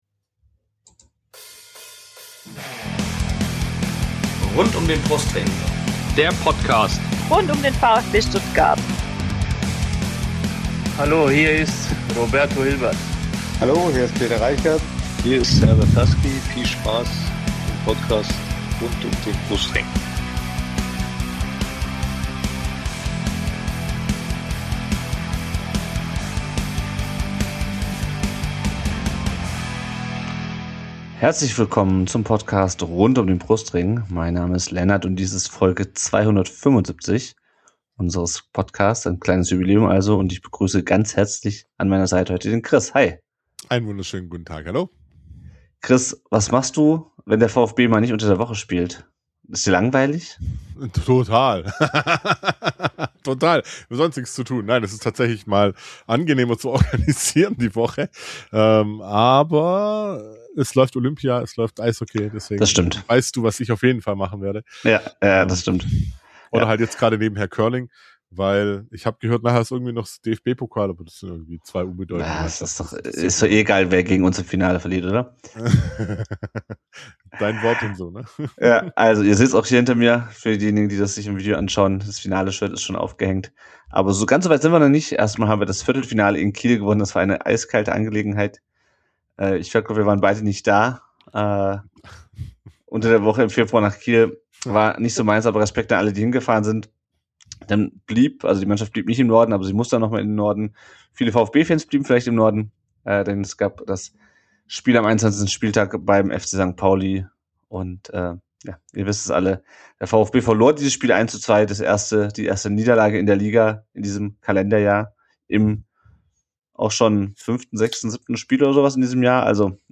Diese Folge konntet Ihr live auf unserem Twitch-Kanal mitverfolgen!